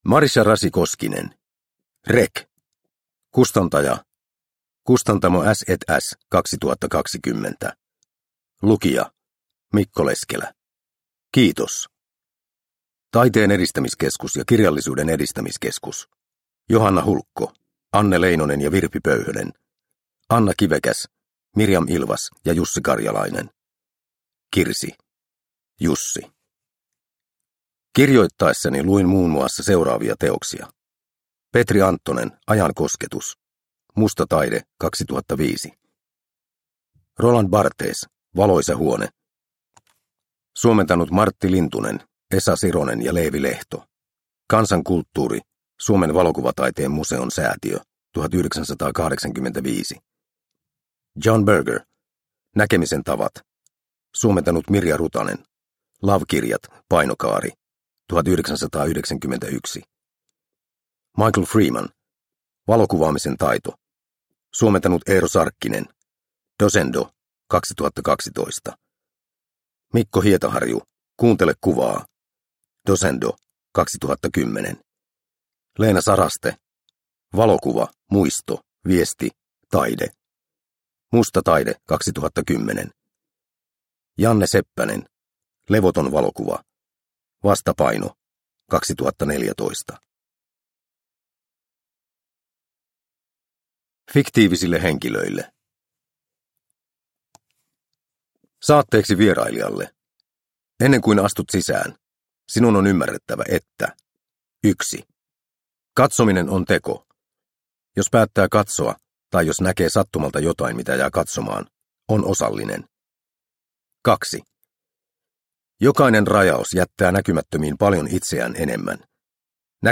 REC – Ljudbok – Laddas ner